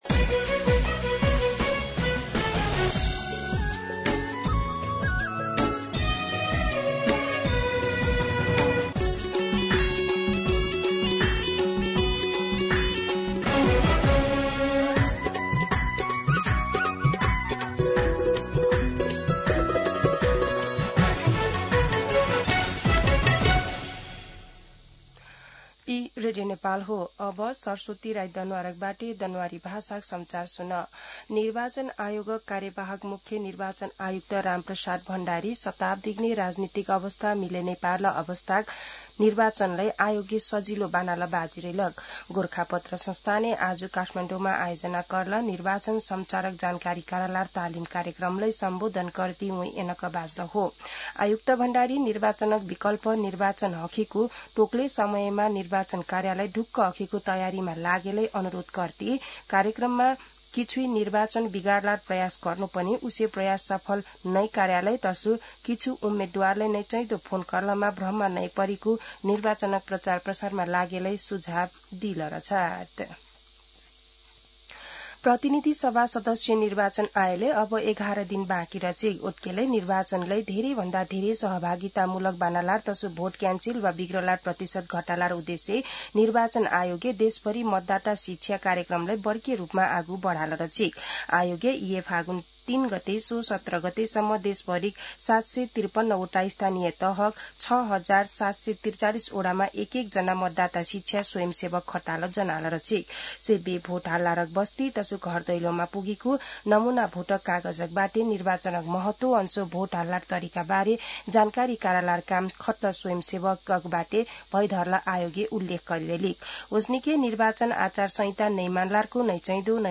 दनुवार भाषामा समाचार : १० फागुन , २०८२
Danuwar-News-1.mp3